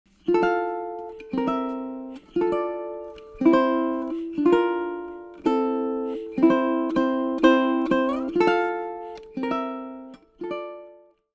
Thus, the Down 2x Strum is recommend: strum downwards every two beats with the thumb (beats 1 and 3).
Down 2X Strum | Down 2X strum with a rhythm fill at the end of the phrase.
Ode_to_Joy_down2xstrum.mp3